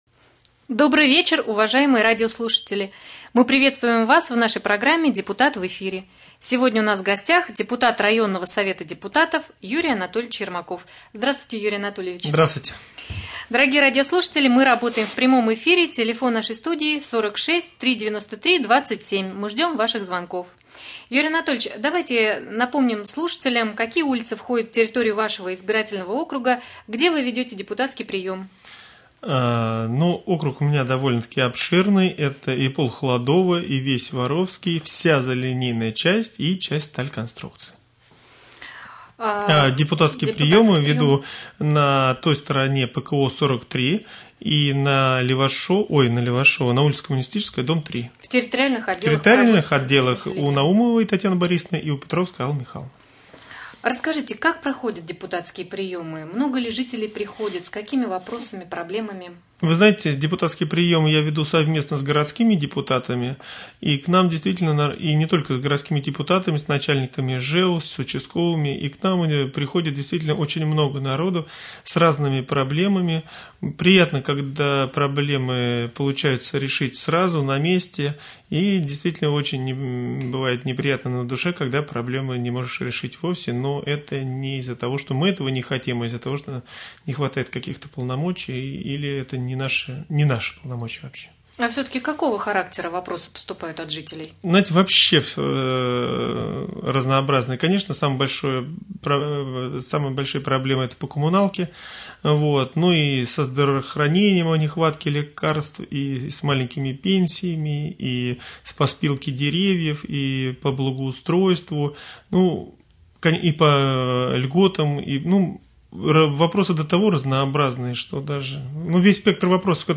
Прямой эфир с депутатом районного Совета депутатов Юрием Анатольевичем Ермаковым.